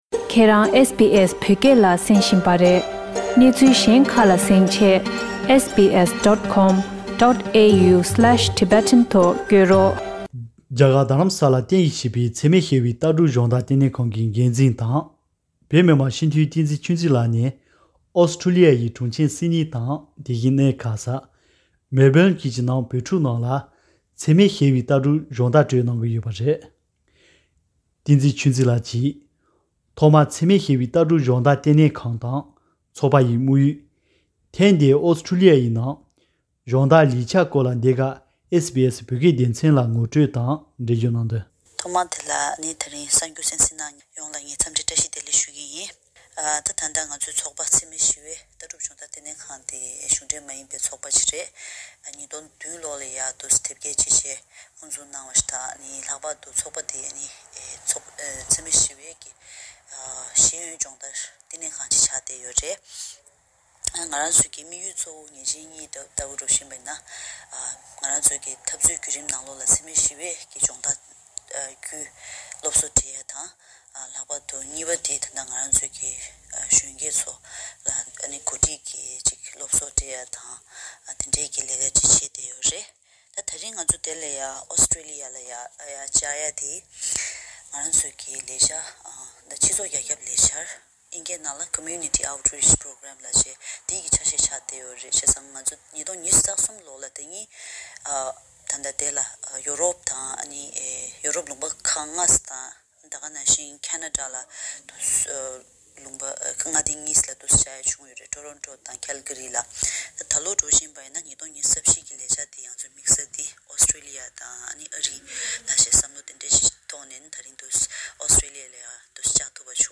རྒྱ་གར་དྷ་རམ་ས་ལར་བརྟེན་གཞི་བྱས་པའི་འཚེ་མེད་ཞི་བའི་ལྟ་གྲུབ་སྦྱོང་བརྡར་བསྟི་གནས་ཁང་གི་འགན་འཛིན་དང་བོད་མི་མང་སྤྱི་འཐུས་བསྟན་འཛིན་ཆོས་འཛིན་ལགས་ནས་ཨོ་སི་ཁྲུ་ལི་ཡའི་གྲོང་ཁྱེར་སིཌ་ནིང་དང་། དེ་བཞིན་གནས་མཁར་གསར། མེལ་བྷེལ་བཅས་ཀྱི་ནང་བོད་ཕྲུག་རྣམས་ལ་འཚེ་མེད་ཞི་བའི་ལྟ་གྲུབ་སྦྱོང་བརྡར་སྤྲོད་གནང་བཞིན་པའི་སྐོར་བཅར་འདྲི།